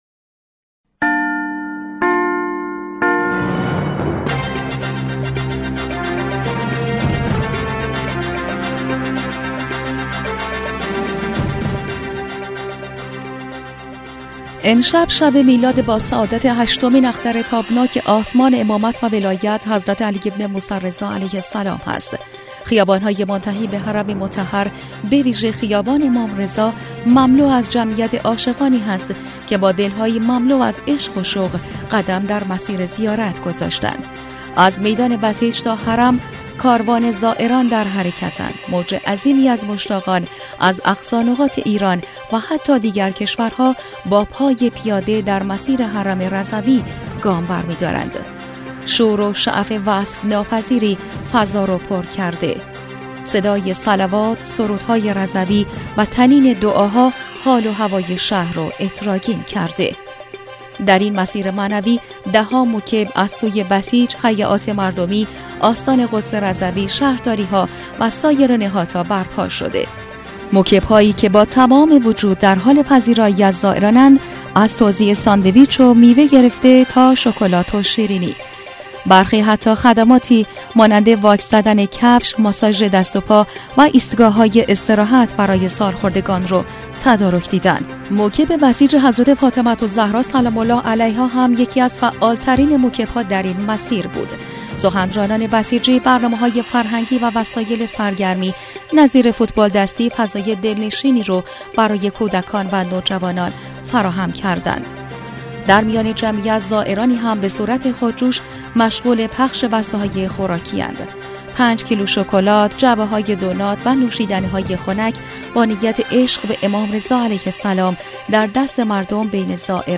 ویژه برنامه جشن خیابانی امام رضا(ع) در مشهد؛
گزارش حال‌وهوای مشهدالرضا در شب میلاد امام رضا علیه‌السلام
شوروشعف وصف‌ناپذیری فضا را پرکرده؛ صدای صلوات، سرودهای رضوی و طنین دعاها حال‌وهوای شهر را عطرآگین کرده است.